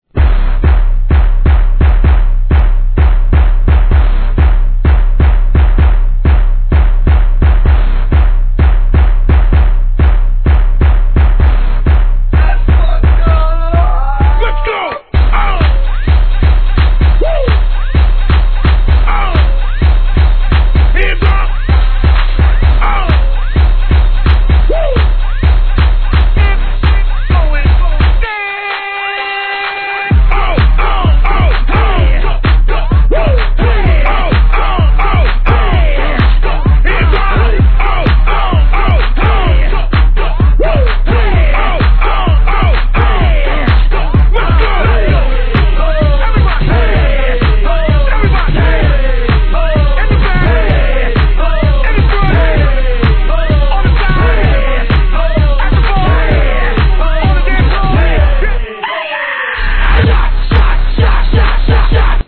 HIP HOP/R&B
大箱DJは必至のチュ〜ンとなったお馴染みの特大BOMB!!